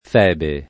Febe [ f $ be ]